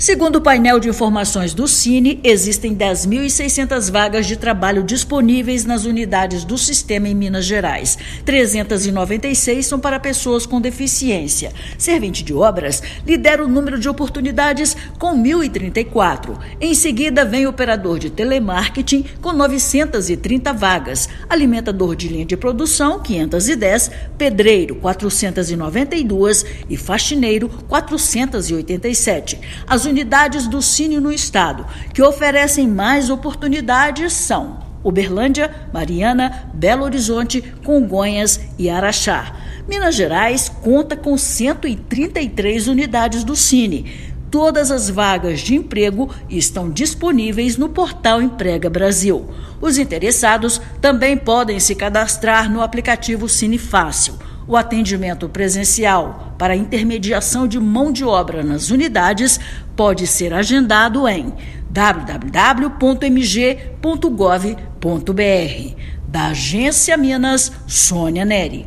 Há mais de 10,6 mil vagas de trabalho disponíveis em postos do Sistema Nacional de Emprego (Sine) em Minas Gerais, segundo o Painel de Informações do sistema. Ouça matéria de rádio.